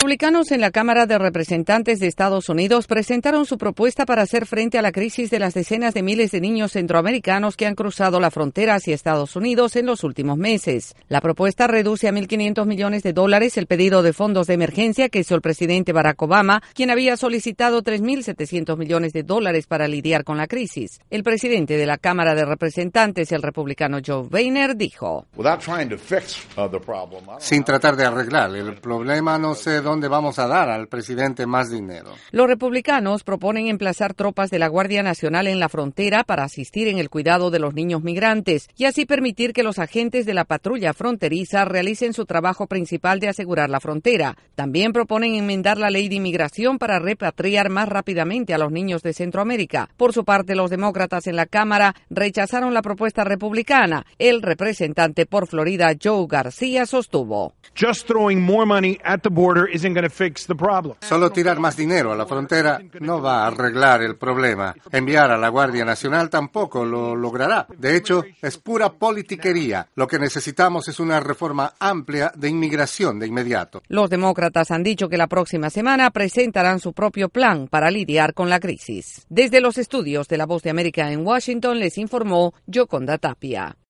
Las diferencias entre republicanos y demócratas sobre el pedido de apoyo económico solicitado por el presidente Barack Obama para enfrentar la crisis en la frontera son muy marcadas y hasta ahora no hay solución. Desde la Voz de América en Washington DC informa